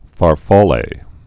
(fär-fälā)